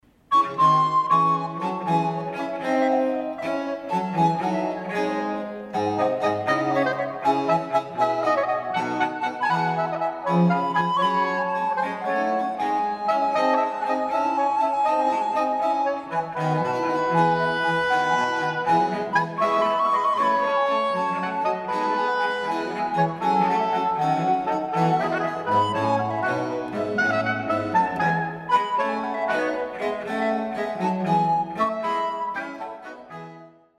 Wesley's Chapel